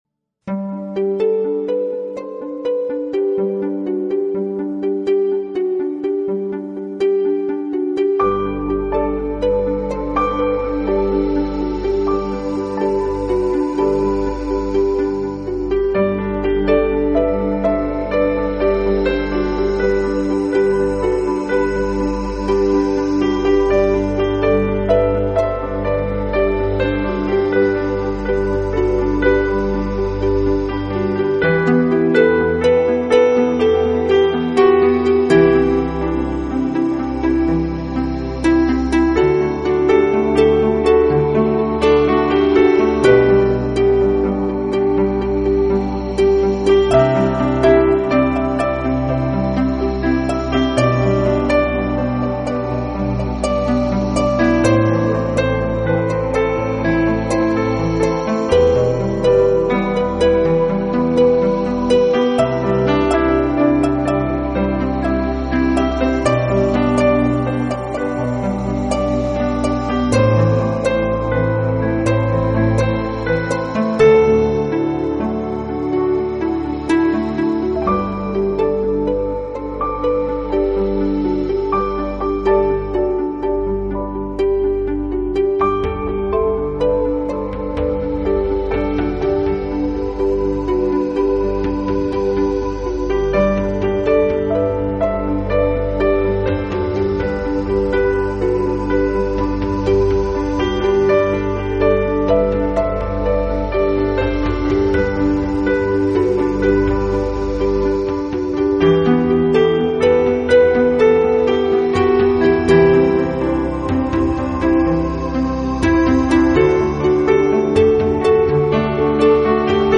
Style: Neo-classic